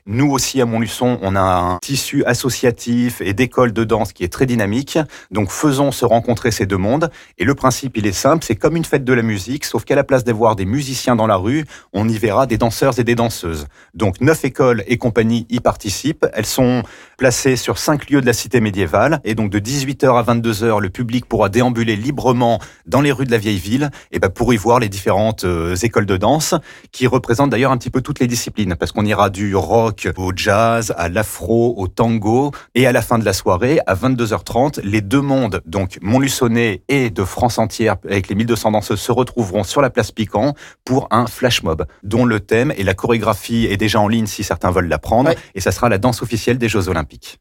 L'adjoint montluçonnais à la culture Alric Berton nous en dit plus ici...